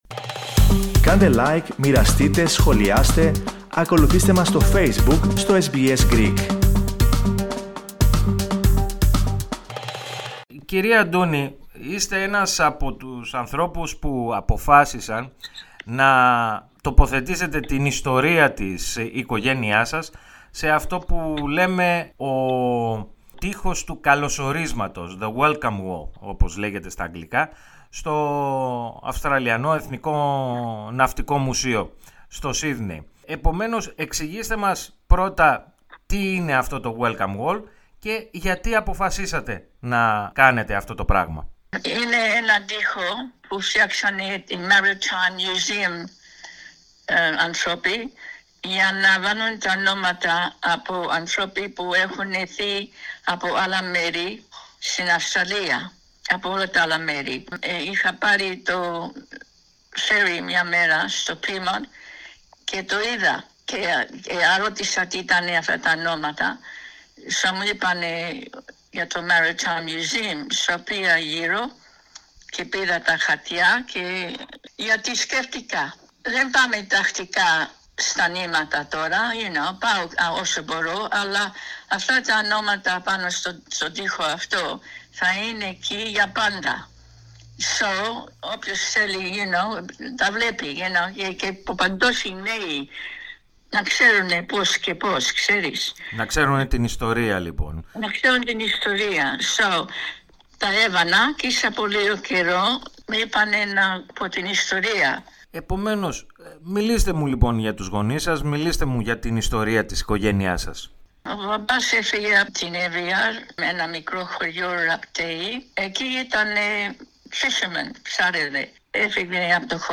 η οποία μίλησε στο Ελληνικό Πρόγραμμα της ραδιοφωνίας SBS.